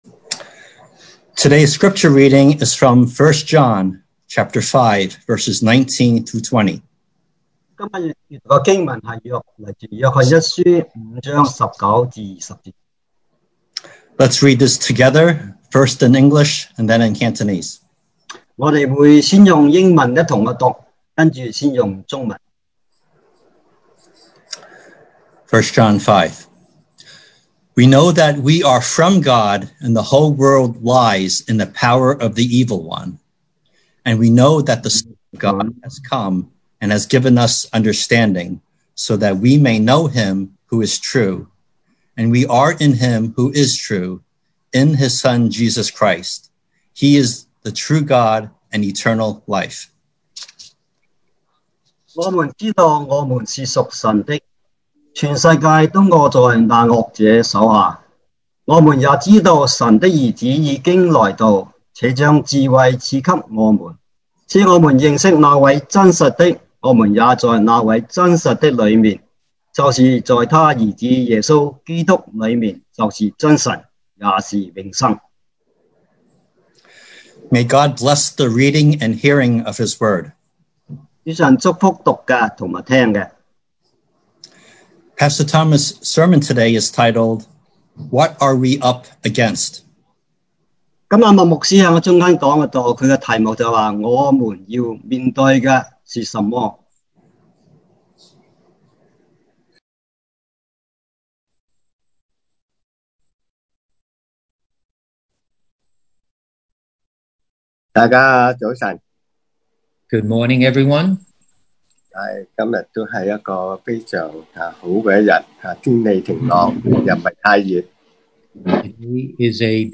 2020 sermon audios 2020年講道重溫 Passage: 1 John 5:19-20 Service Type: Sunday Morning What Are We Up Against?